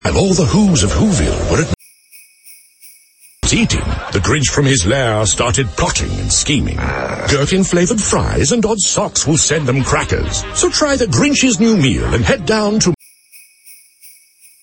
Which (jingle-belled-out) fast-food brand does this Christmas ad belong to?
mackers-w-bells.mp3